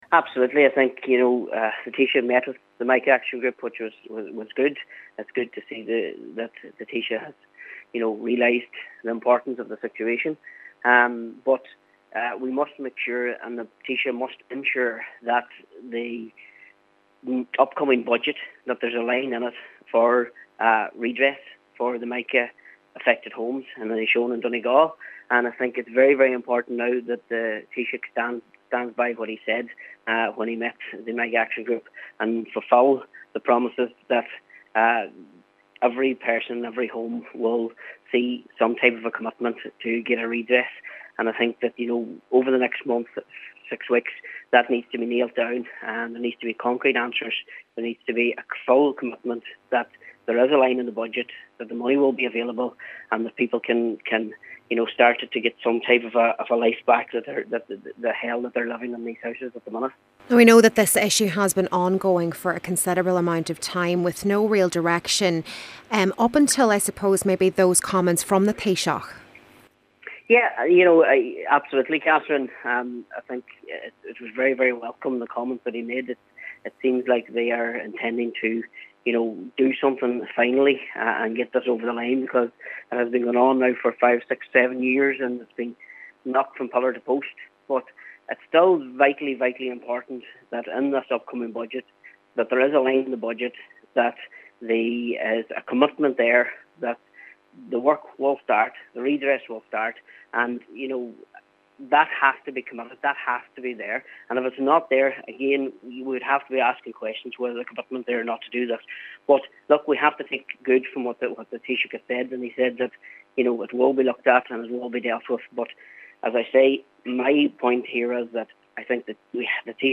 He says including such a scheme in Budget 2019 is vital to address the issue once and for all: